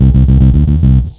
FINGERBSS2-R.wav